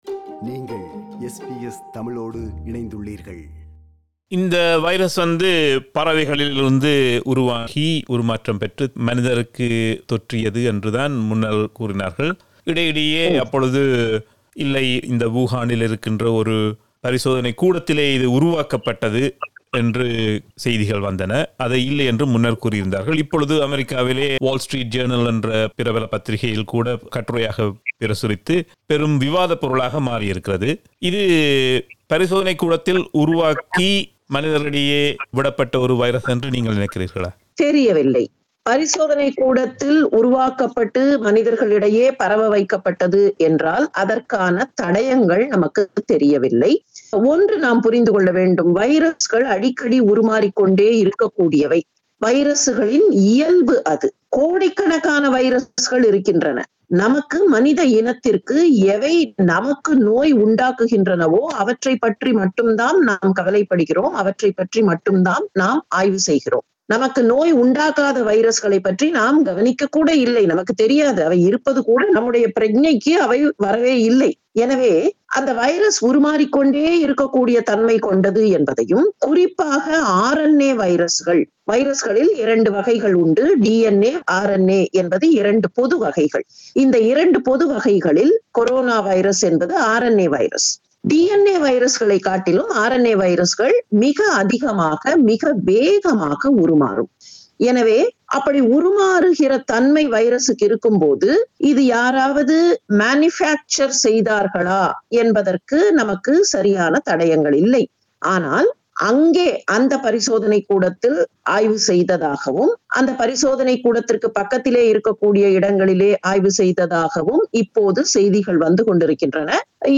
இரண்டு பாகங்களாகப் பதிவாகியிருக்கும் உரையாடலின் நிறைவுப் பாகம் இது. கொரோனாவைரஸ் ஒரு ஆய்வகத்தில் உருவாக்கப்பட்டதா, ஒருவரின் நோய் எதிர்ப்பு சக்தியை அதிகரிக்க சோயா பால் எப்படி உதவுகிறது, நாம் அனைவரும் எவ்வாறு பாதுகாப்பாக இருக்க முடியும் என்பவற்றை தெளிவுபடுத்துகிறார்.